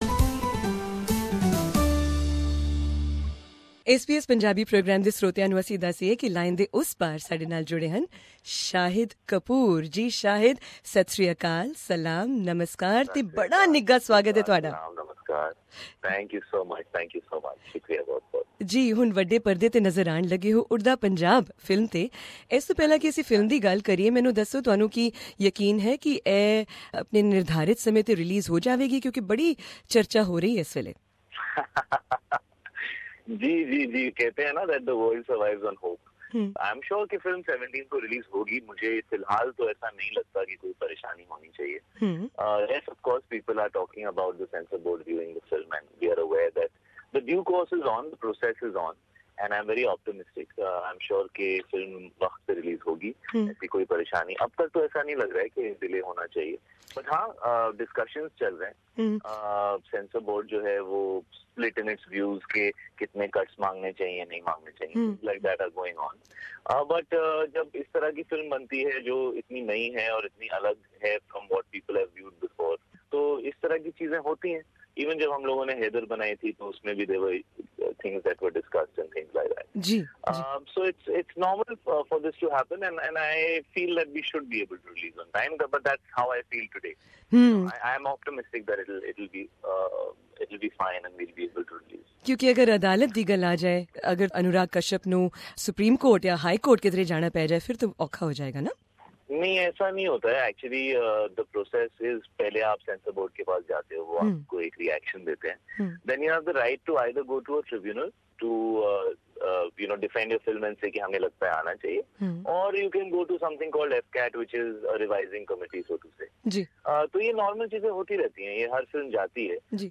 Speaking exclusively to SBS Punjabi program, Shahid Kapoor said that playing the role of drug addict Tommy Singh in the film Udta Punjab was perhaps the biggest challenge for him in his Bollywood career.